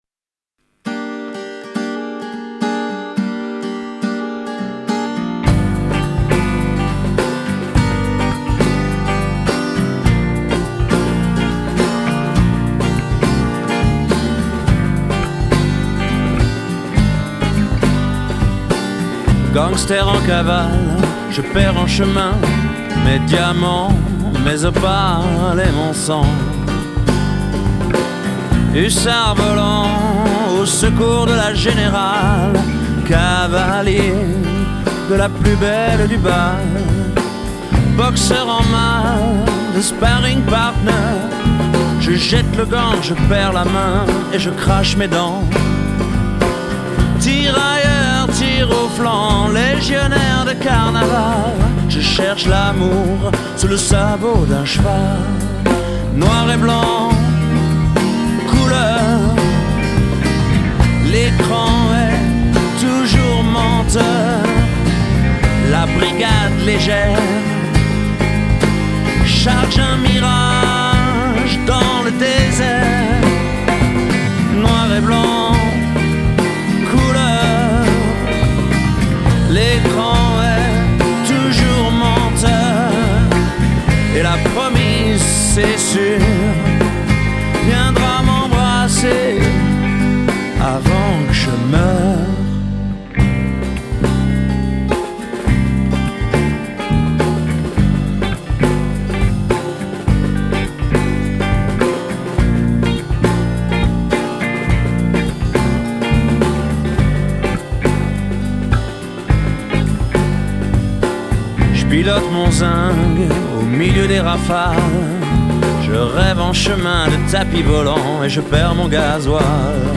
enregistré en 2003 au studio du moulin
guitare, chant
basse
batterie